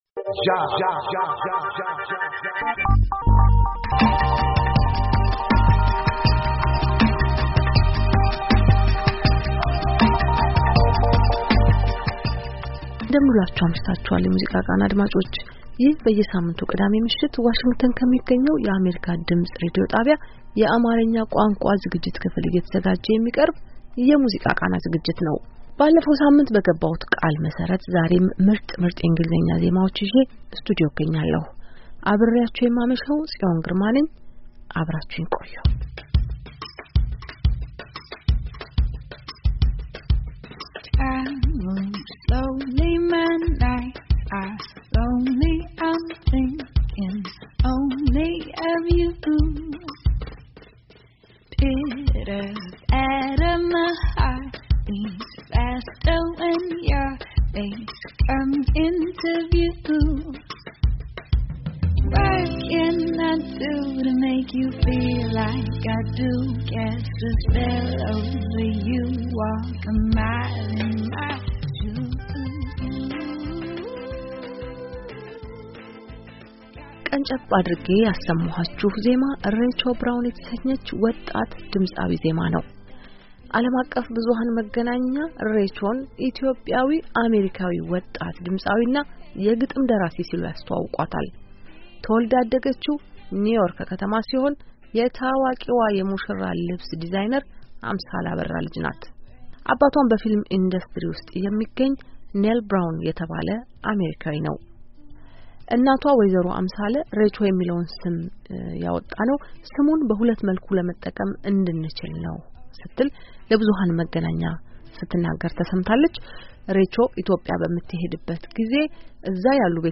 ሁለት ድምፃውያን በቅብብል የሚያጫወቷቸው ምርጥ፤ምርጥ ዜማዎች ተመርጠውላችኋል ተጋበዙ፡፡